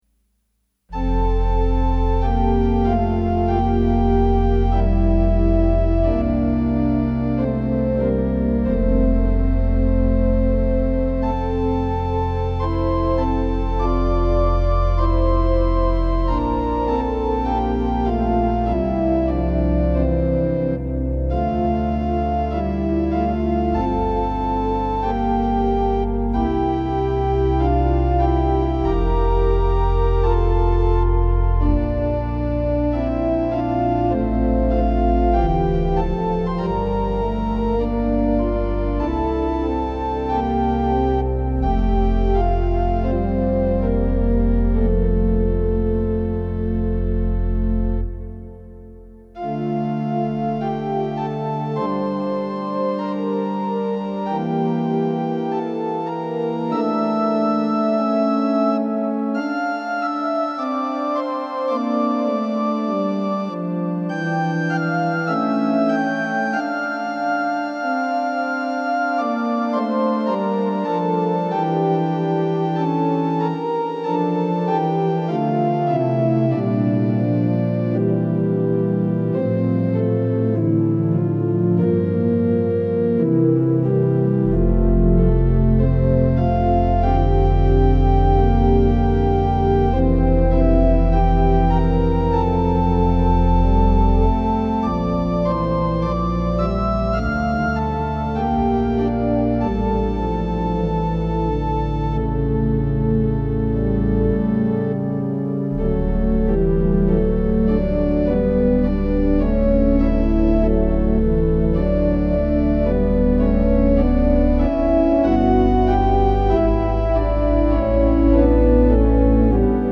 Organ Interludes Audio Gallery